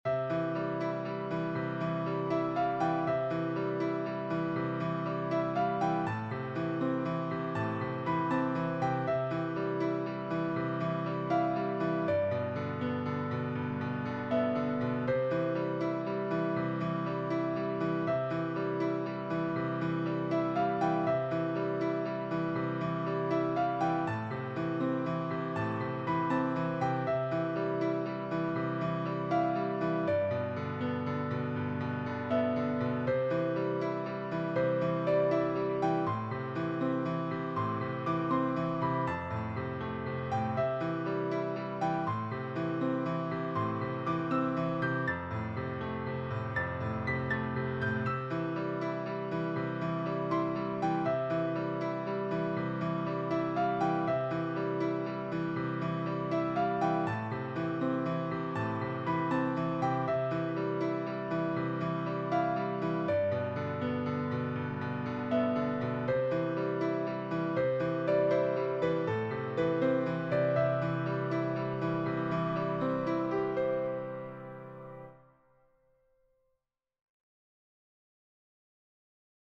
Die Ballade eignet sich besonders gut dazu, große Griffe auf dem Klavier zu üben.